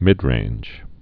(mĭdrānj)